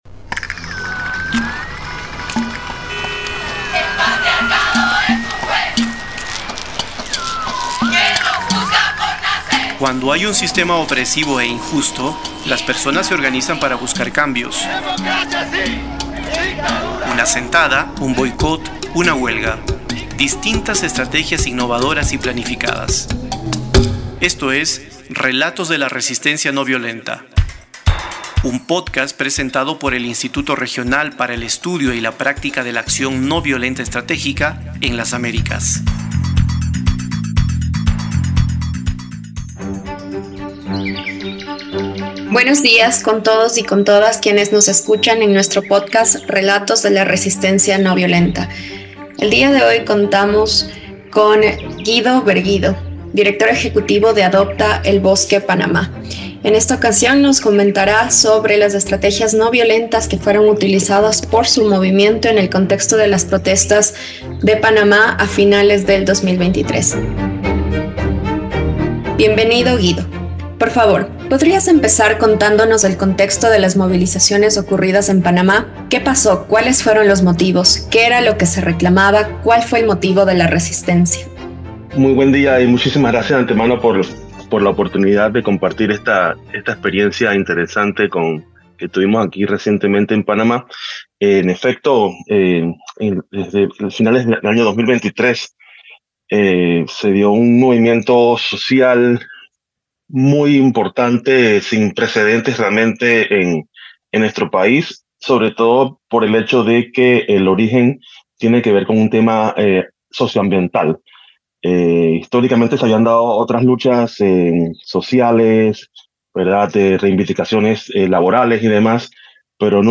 Tamaño: 42.62Mb Formato: Basic Audio Descripción: Entrevista - Acción ...